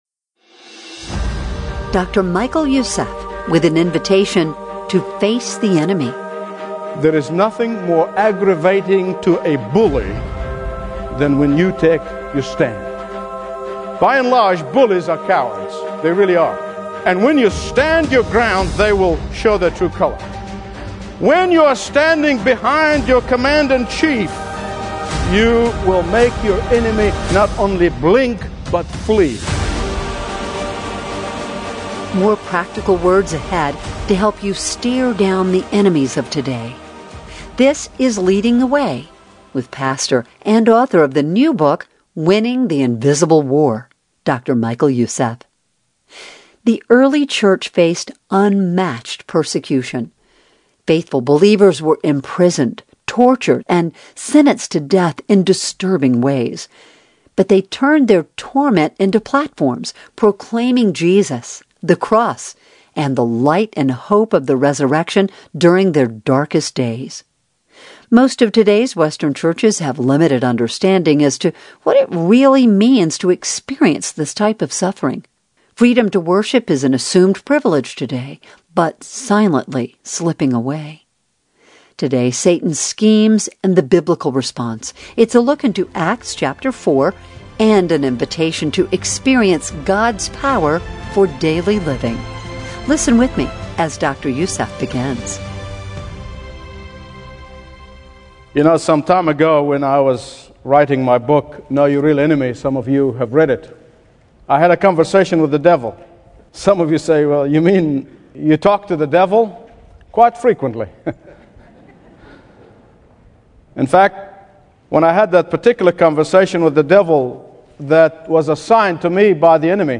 Preaching from Acts 4, he shows how Peter and the apostles responded to persecution with boldness, unity, and Spirit-filled courage.